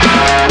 16 Foyer Distortion Shard.wav